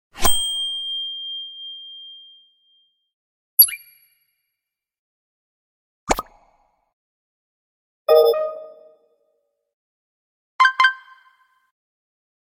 HQ Sound Effects for App sound effects free download
HQ Sound Effects for App Notifications.